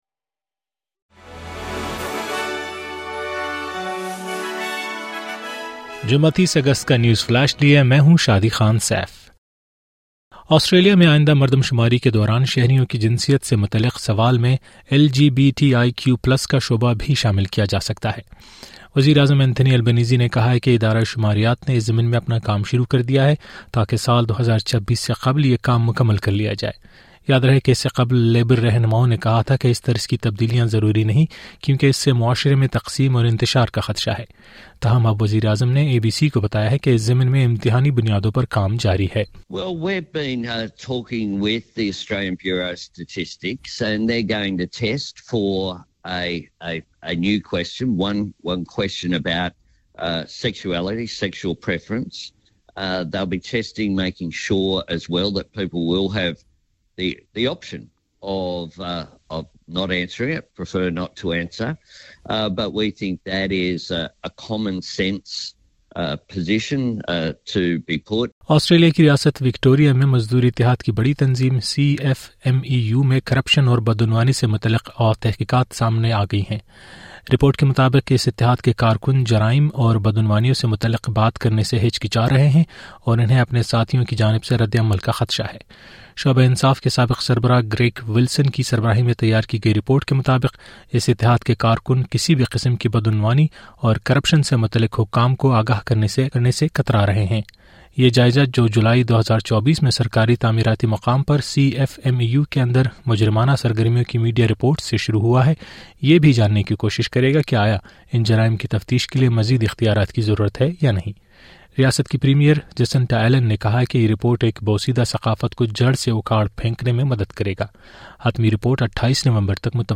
نیوز 30 اگست : آسٹریلیا میں آئندہ مردم شماری کے دوران شہریوں کی جنسیت سے متلعق نیا سوال